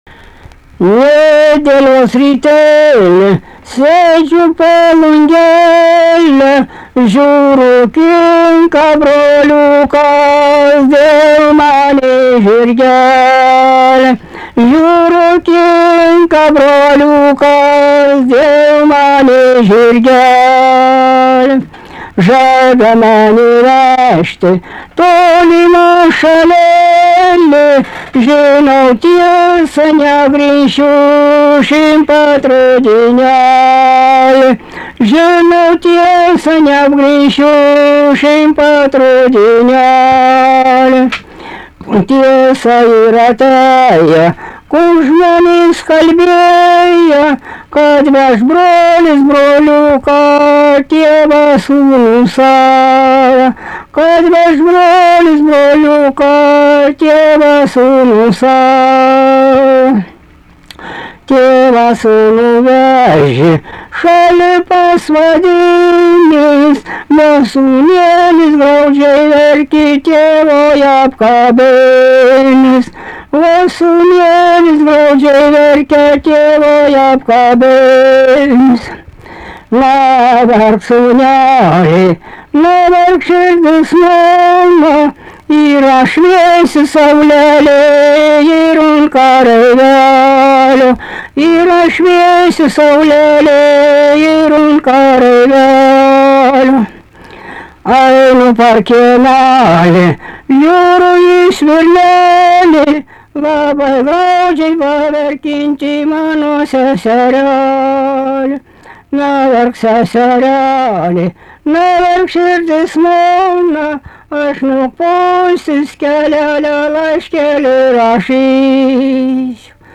daina, vestuvių